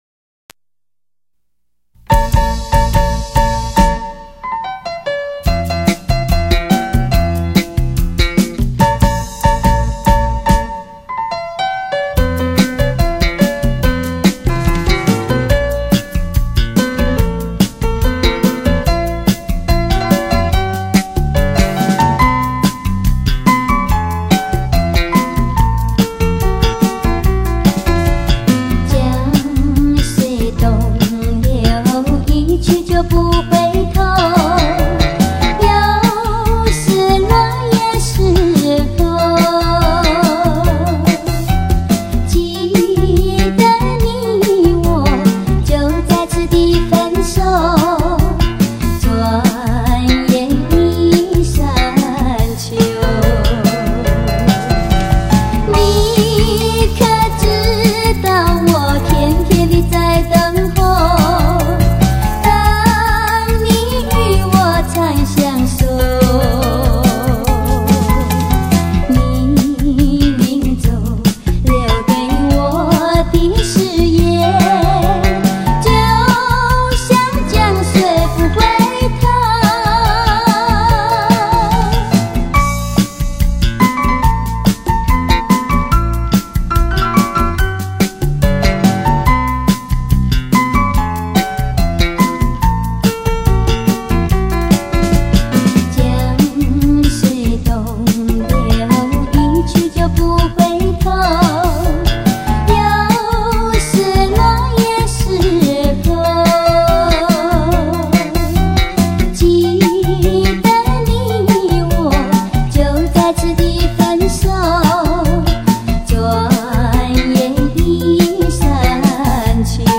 钢琴版精选
音质很好！